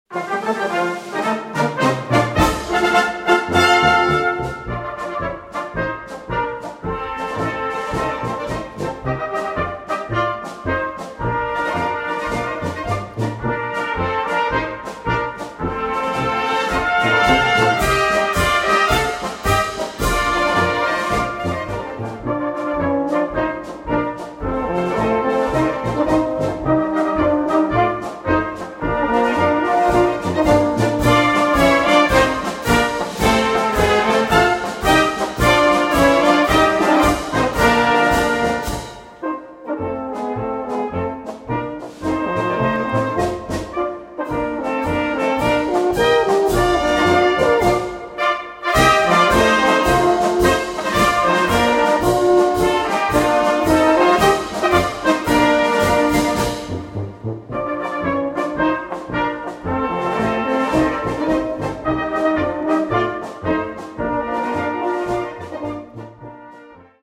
Gattung: Polka
Besetzung: Blasorchester
Eine gemütliche, abwechslungsreiche Polka